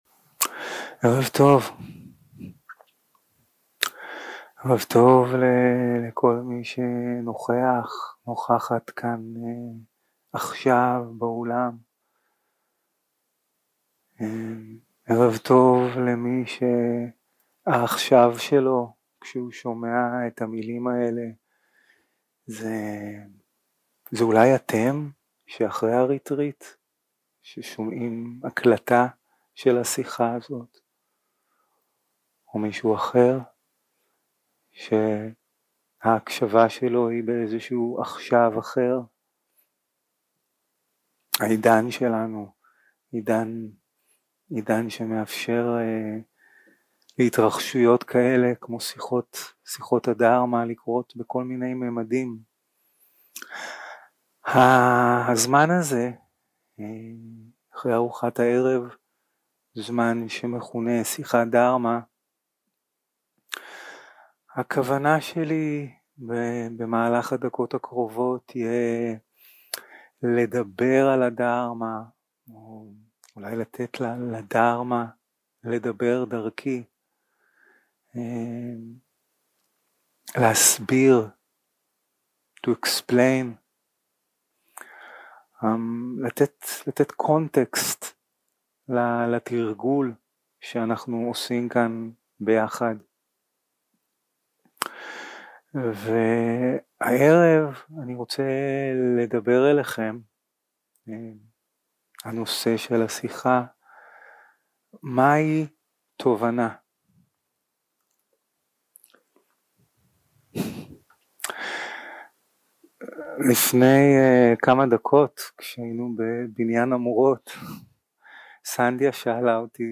יום 2 – הקלטה 4 – ערב – שיחת דהארמה - מהי תובנה
סוג ההקלטה: שיחות דהרמה